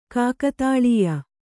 ♪ kākatālīya